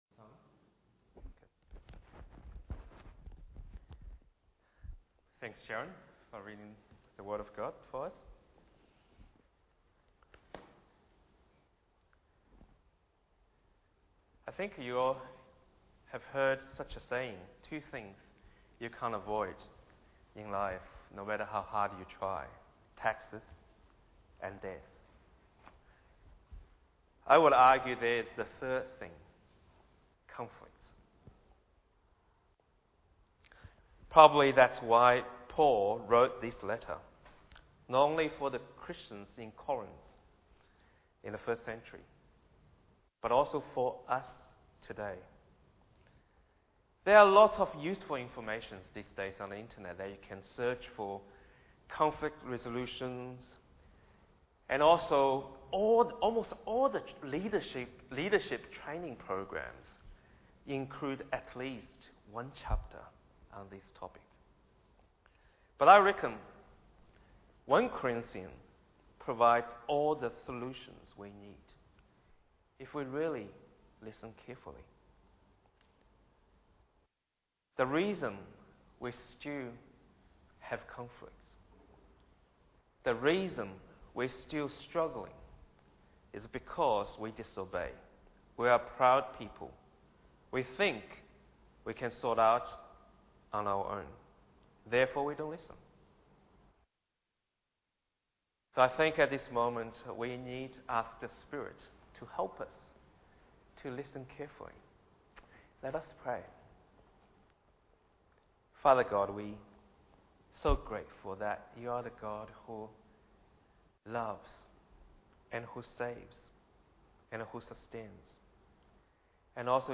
Bible Text: 1 Corinthians Chapter 1 | Preacher